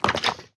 Minecraft Version Minecraft Version latest Latest Release | Latest Snapshot latest / assets / minecraft / sounds / mob / wither_skeleton / step4.ogg Compare With Compare With Latest Release | Latest Snapshot